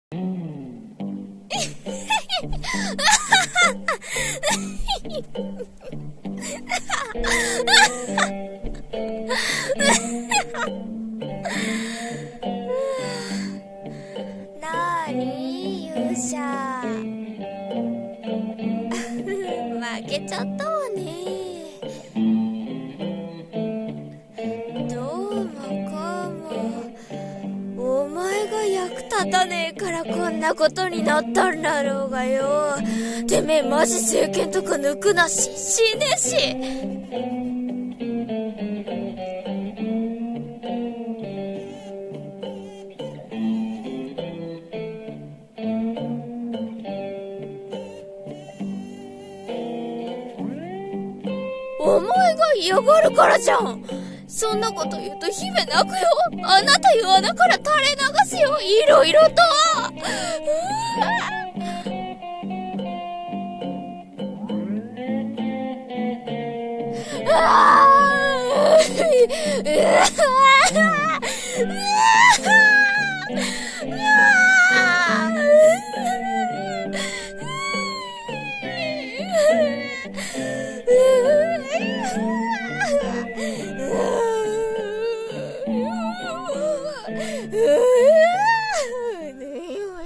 ギャグ声劇「勇者も姫も笑うしかねぇ」